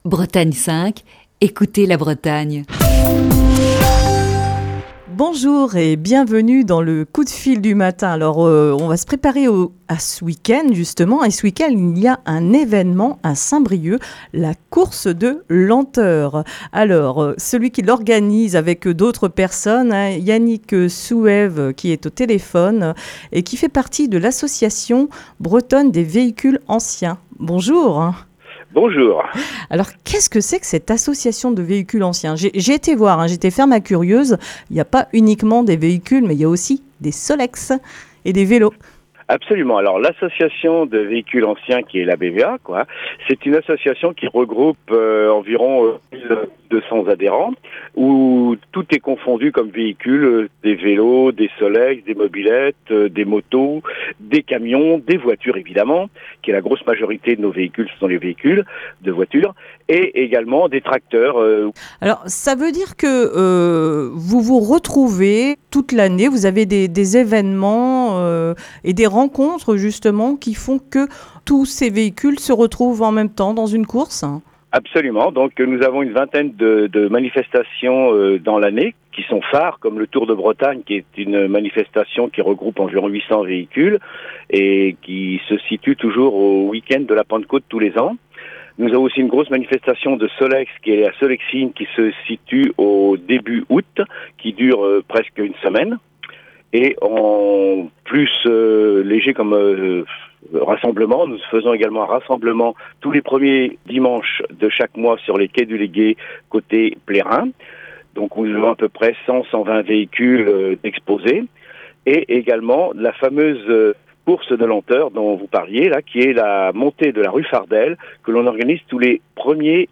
au téléphone